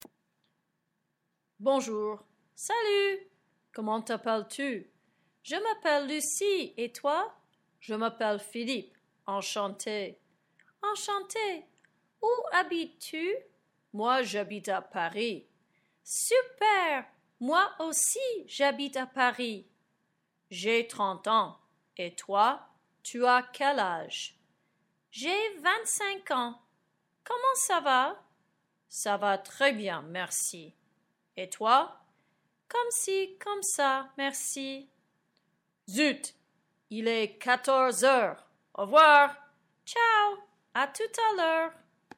basic conversation between 2 people